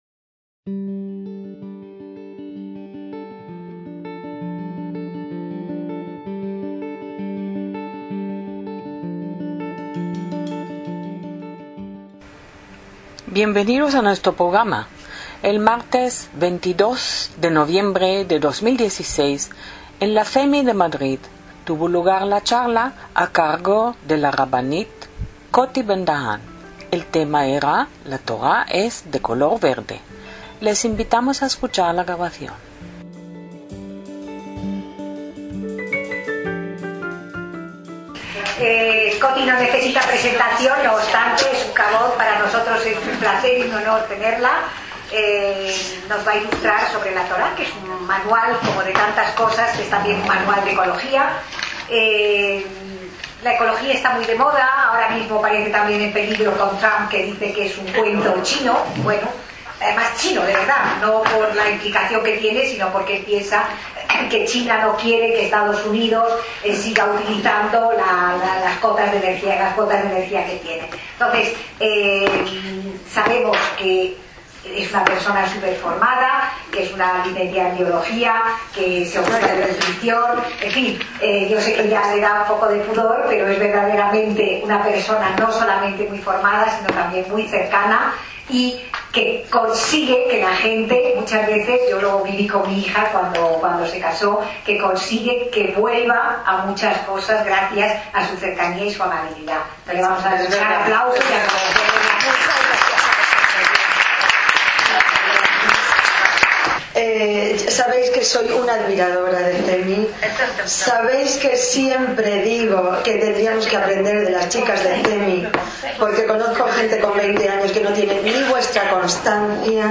ACTOS EN DIRECTO - La eterna discusión que se plantea desde el comienzo de la humanidad: ¿La naturaleza pertenece al hombre o el hombre pertenece a la naturaleza? Una interesante visión desde el punto de vista de la Torá.